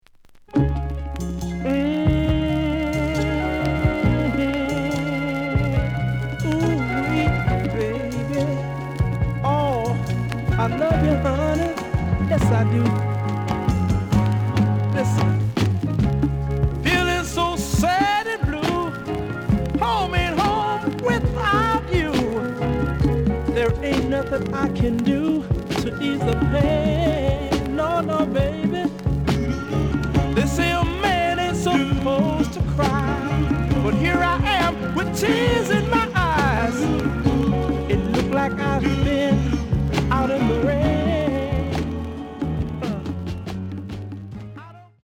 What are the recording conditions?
The audio sample is recorded from the actual item. Slight noise on both sides.)